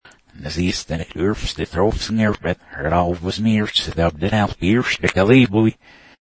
Reverse Audio